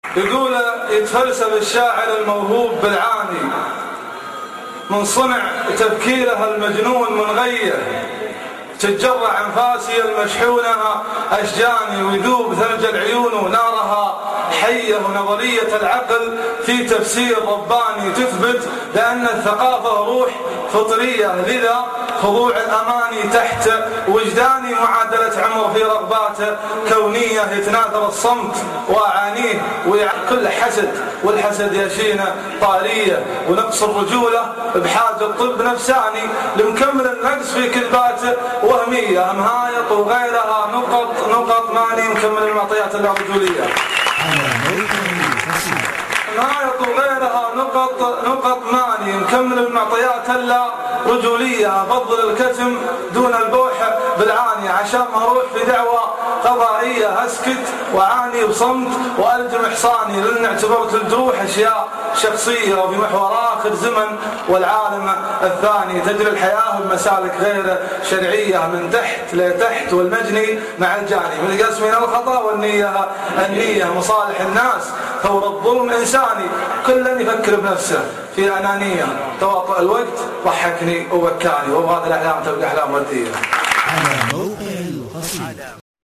يتفلسف الشاعر الموهوب ( اصبوحة جامعة الكويت )   05 ابريل 2012